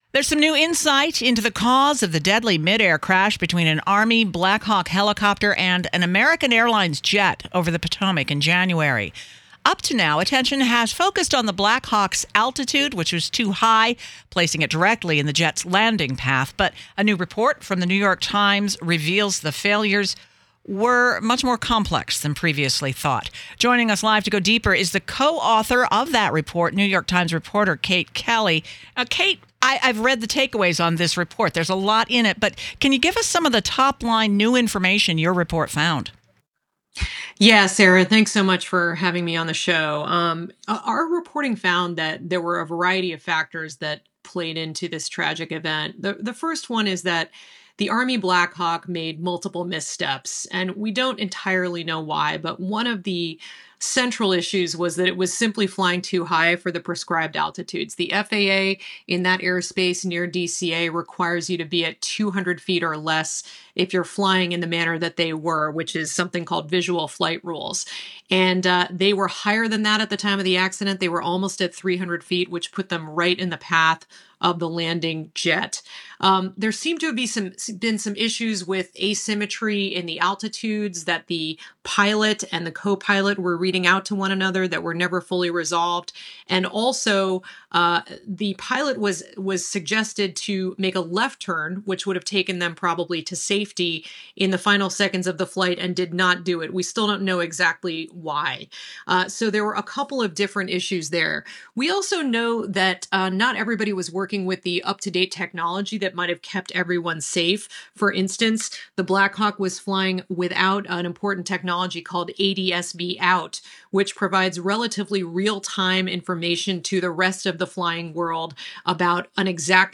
This interview has been lightly edited for clarity.